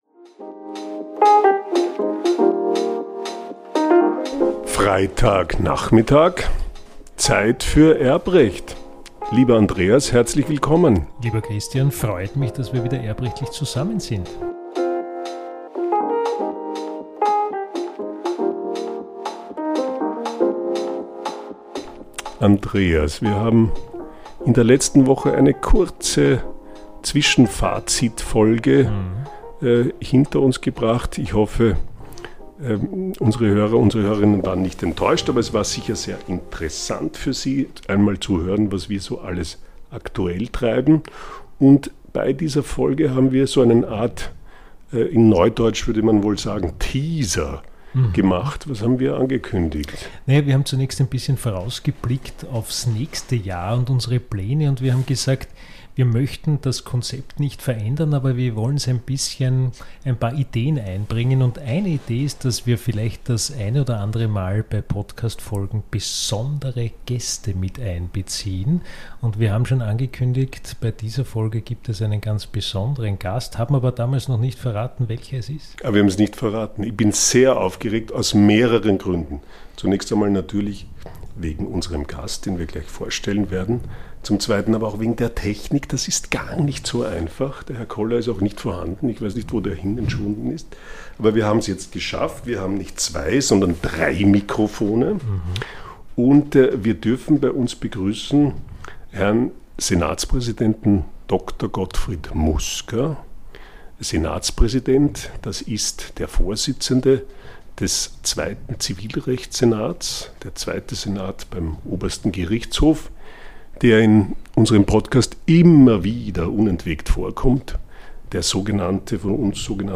Der Vorsitzende des Erbrechtssenats spricht mit uns über das Höchstgericht